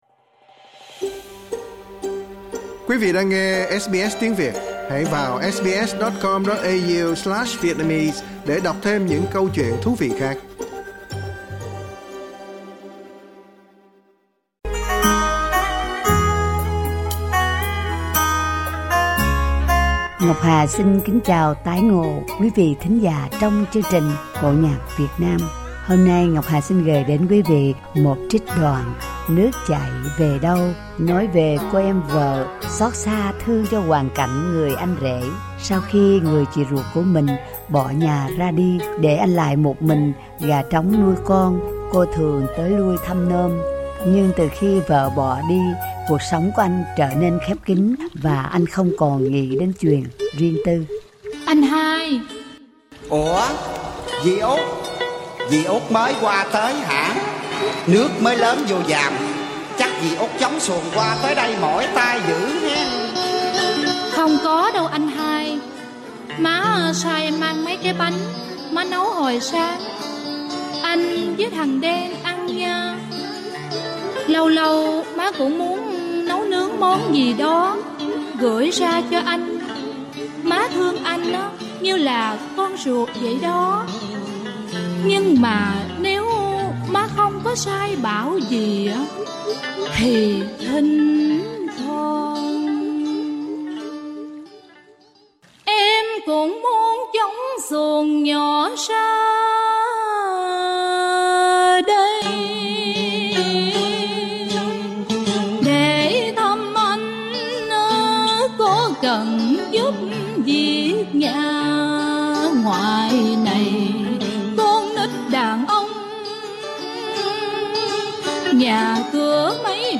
trích đoạn vở Cải Lương